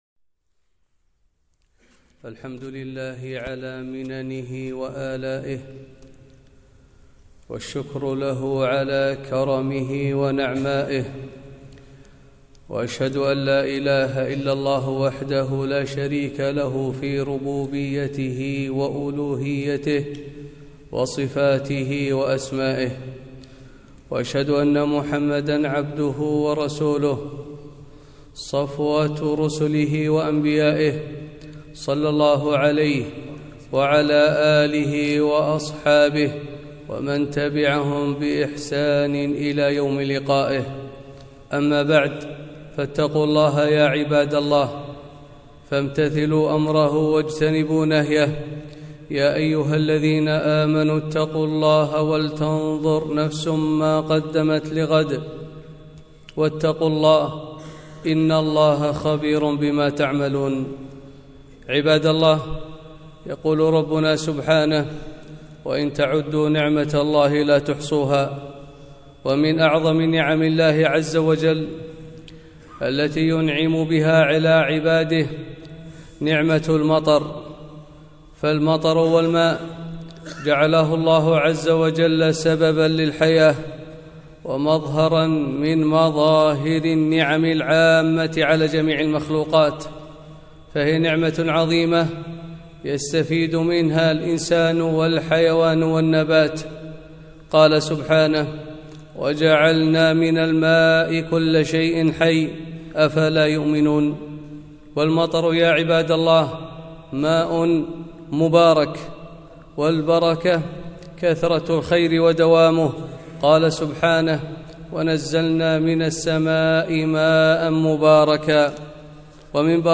خطبة - المطر نعمة وآية